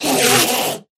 Endermen Scream 3